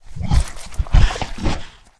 boar_eat_3.ogg